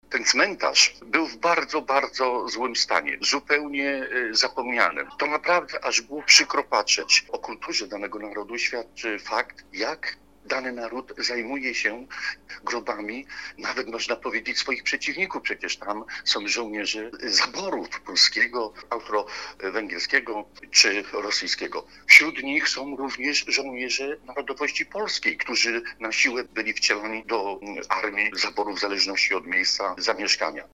Władze gminy Zapolice, postanowiły odnowić go i przywrócić mu miejsce w historii – mówi wójt tej gminy Witold Oleszczyk.